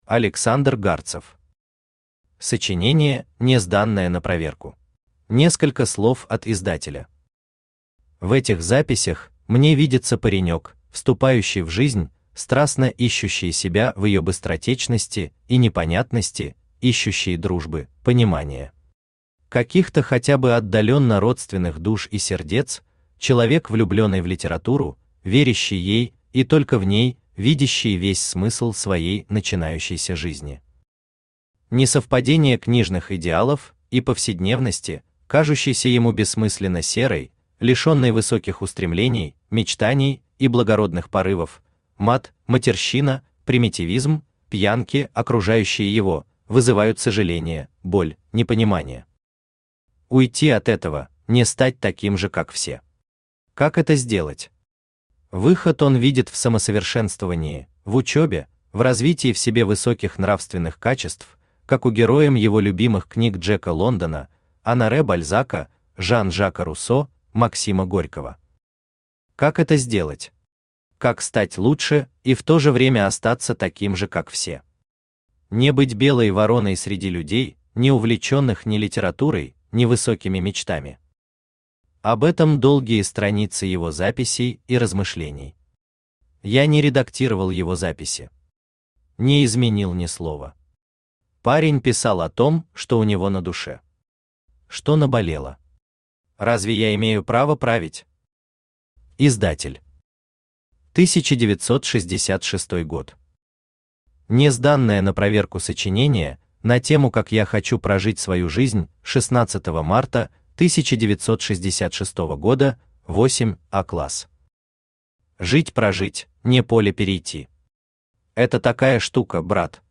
Аудиокнига Сочинение, не сданное на проверку | Библиотека аудиокниг
Aудиокнига Сочинение, не сданное на проверку Автор Александр Гарцев Читает аудиокнигу Авточтец ЛитРес.